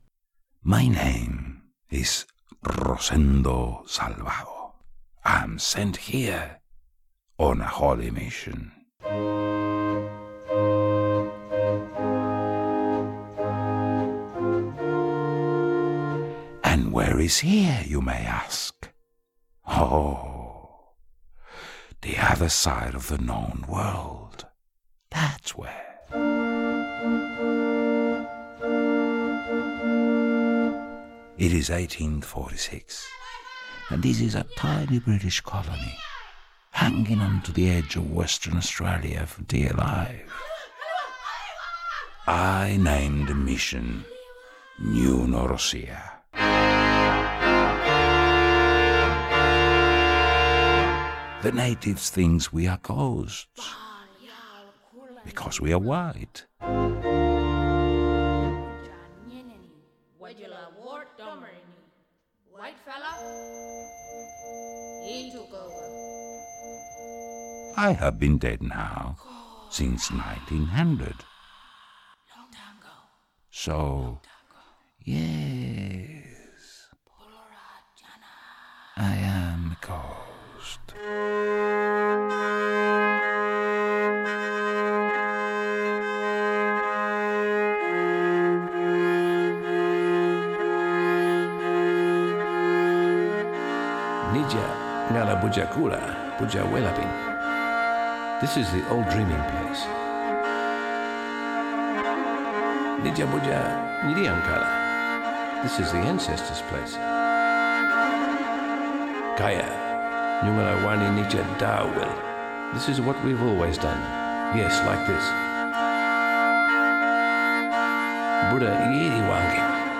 The Jon Rose Web Archive, Radio Violin - Salvado, BBC, New Norcia, First Aboriginal String Orchestra, Aboriginal brass band, religion, Western Australia, colonial music, tribal, Nyungar language, monks